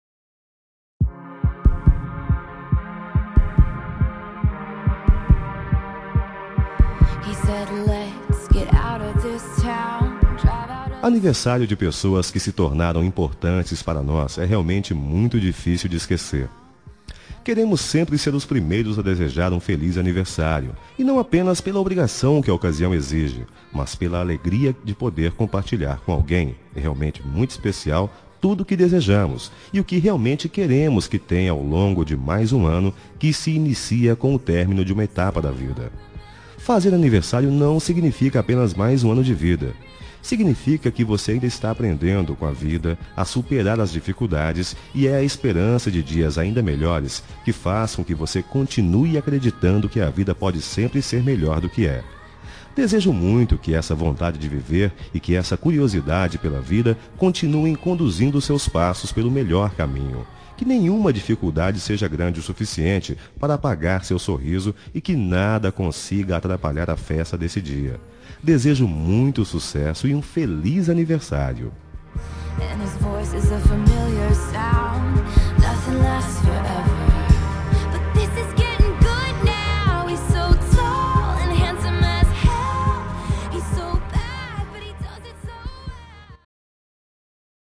Telemensagem de Aniversário de Pessoa Especial – Voz Masculino – Cód: 1906